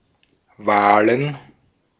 Ääntäminen
Ääntäminen Tuntematon aksentti: IPA: /ˈvaːlən/ Haettu sana löytyi näillä lähdekielillä: saksa Käännöksiä ei löytynyt valitulle kohdekielelle. Wahlen on sanan Wahl monikko.